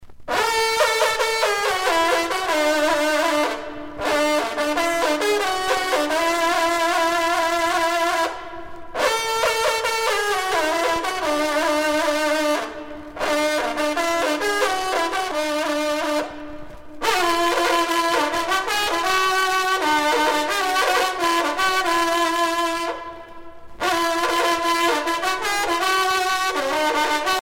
sonnerie vénerie - fanfare d'équipage
Pièce musicale éditée